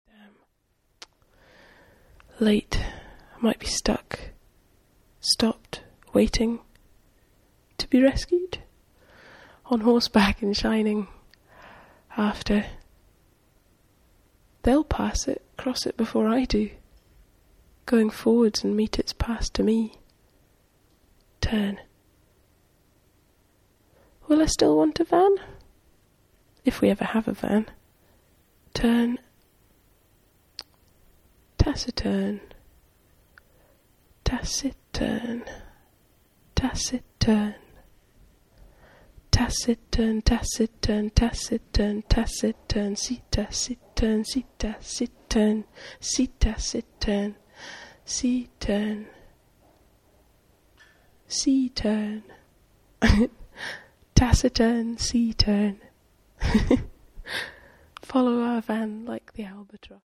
6-channel sound installation, duration 8'07".